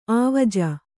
♪ āvaja